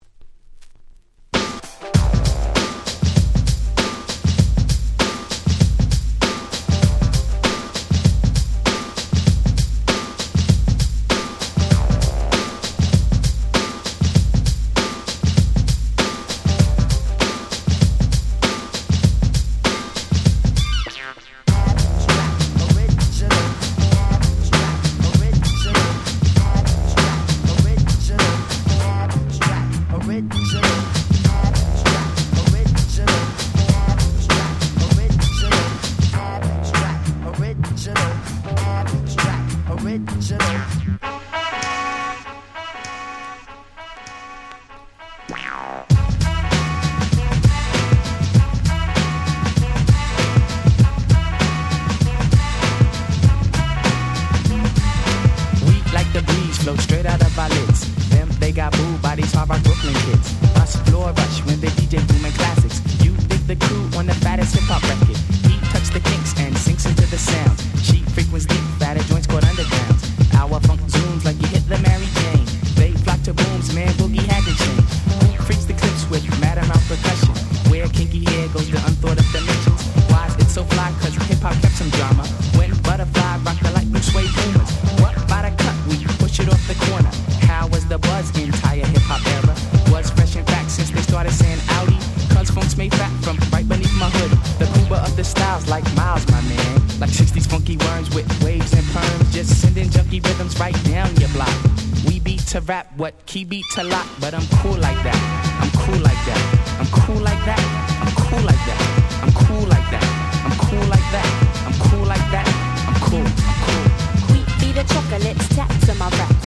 ウィキードミックス 90's Boom Bap ブーンバップ R&B 勝手にRemix 勝手にリミックス ミックス物